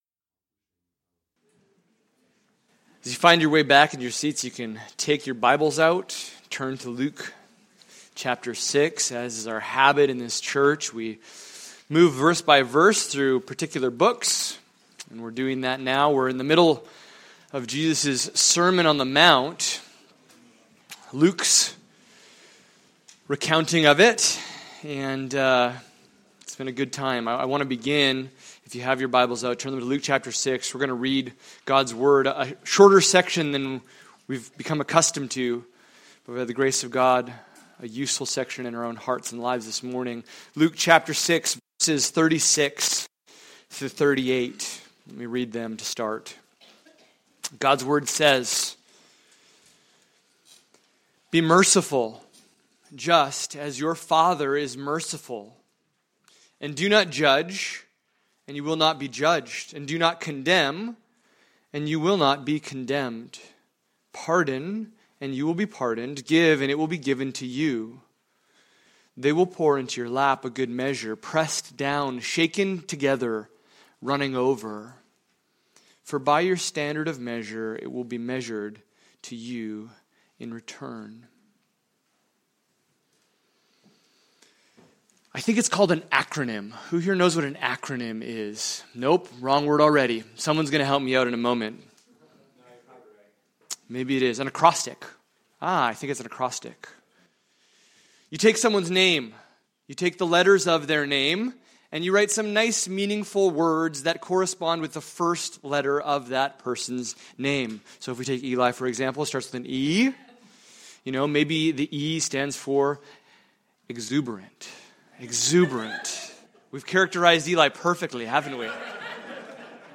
Luke 6:36-38 Service Type: Sunday Morning « Confirming the Mission in the Face of Opposition The Kingdom Paradox